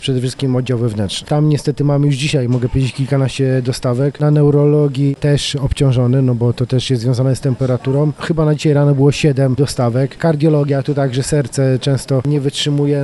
Coraz więcej z nich trafia na oddziały szpitalne mówi wiceprezydent Jerzy Zawodnik: